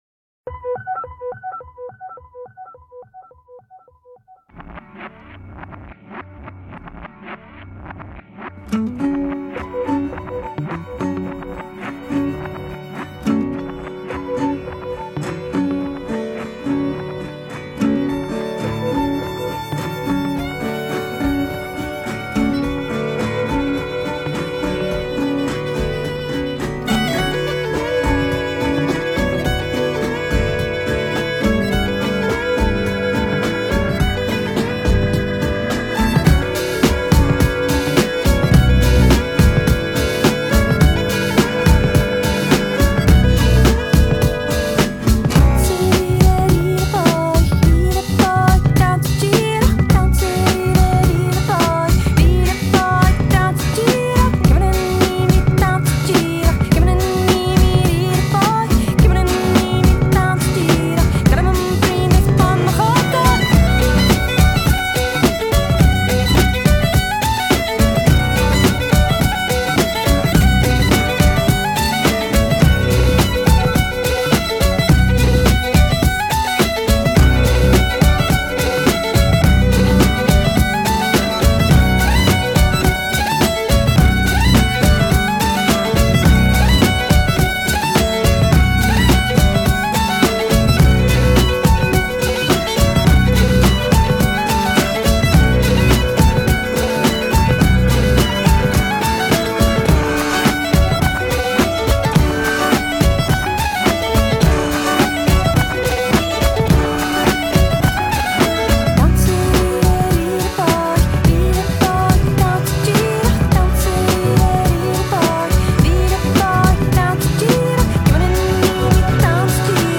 听声音应该是个法国的年轻女生 偏流行音乐 但充满了celtic味道 编曲极有创意